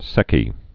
(sĕkē)